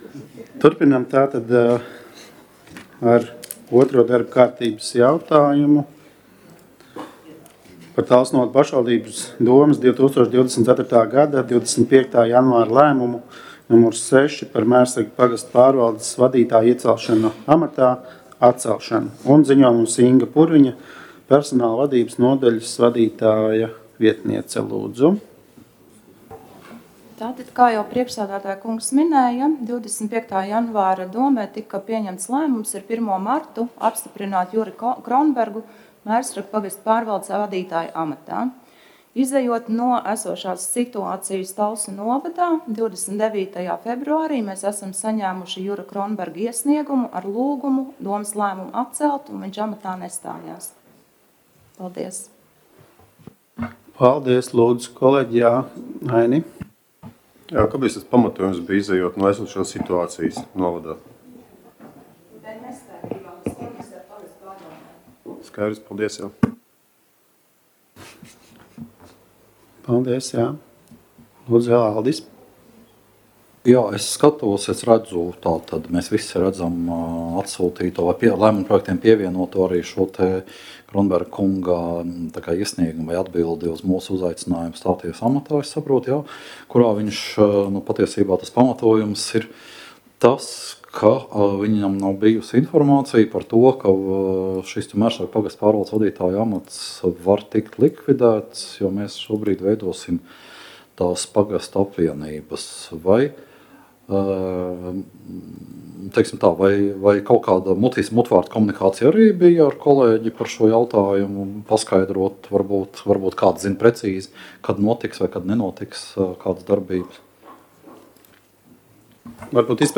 Talsu novada domes sēde Nr. 10